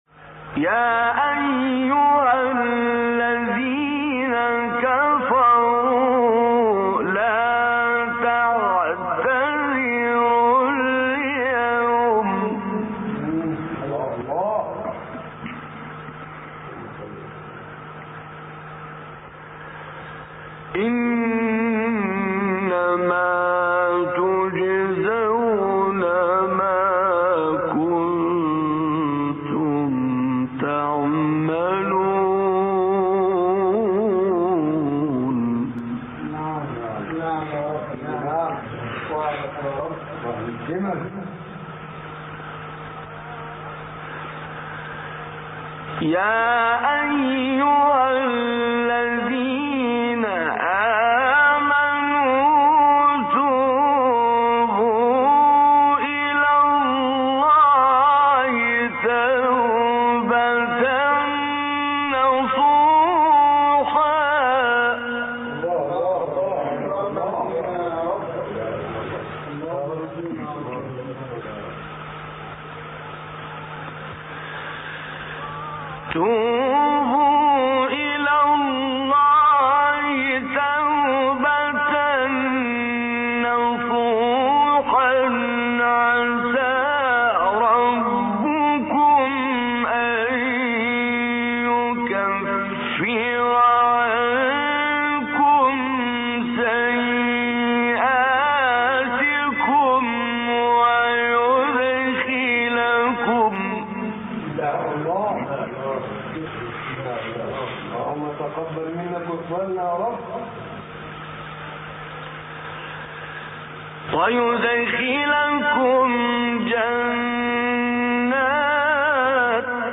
مقطعی از سوره تحریم با صدای طنطاوی | نغمات قرآن
سوره : تحریم آیه : 7-8 استاد : طنطاوی مقام : سه گاه قبلی بعدی